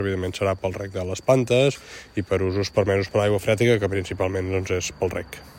L’alcalde ha confirmat que l’aigua captada s’utilitzarà després per als usos que permet la normativa vigent: